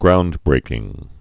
(groundbrākĭng)